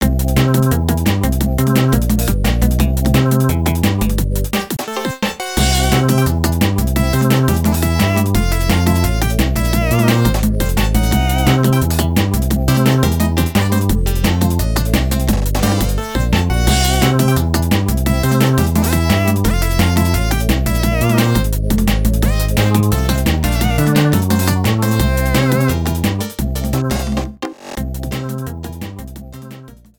Cropped to 30 seconds, fade out added